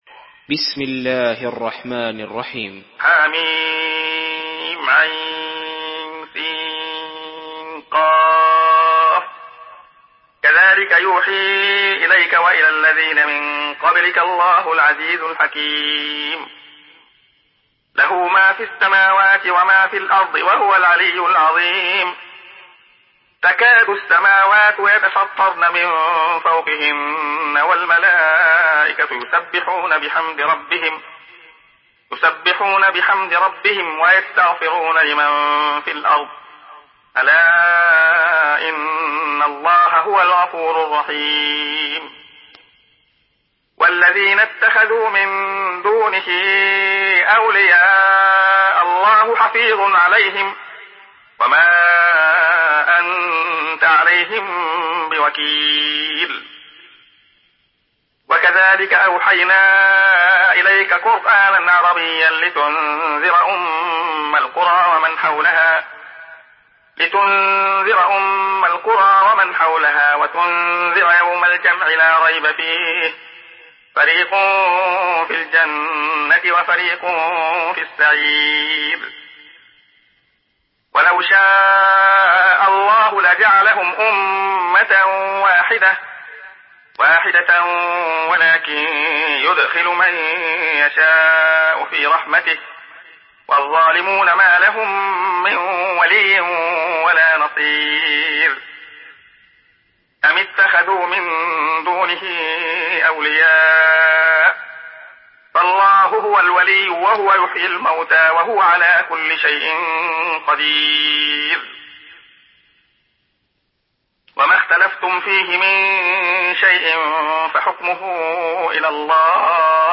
Surah আশ-শূরা MP3 by Abdullah Khayyat in Hafs An Asim narration.
Murattal Hafs An Asim